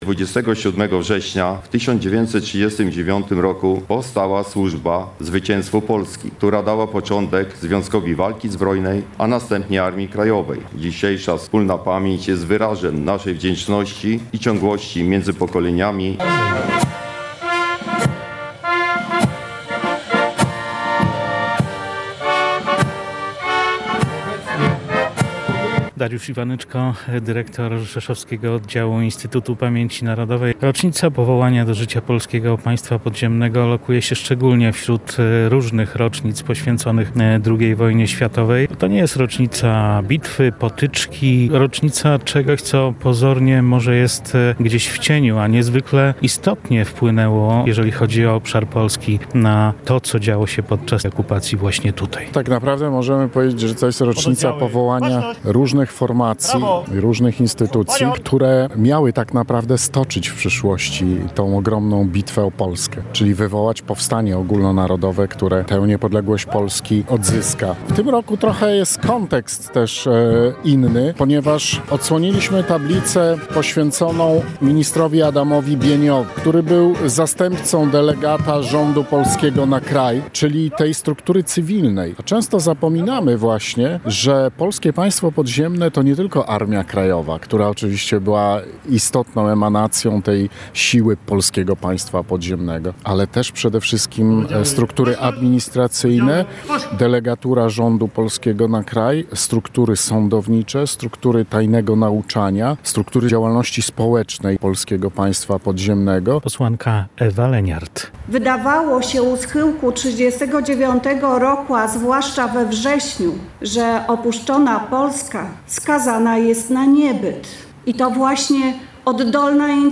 Apel Pamięci i zakończenie obchodów Rzeszowskie obchody zakończył Apel Pamięci i salwa honorowa oraz złożenie pod Pomnikiem wieńców i wiązanek kwiatów.
Relacja